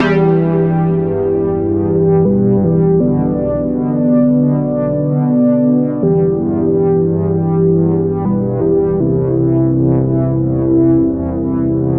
Tag: 80 bpm Chill Out Loops Synth Loops 2.02 MB wav Key : E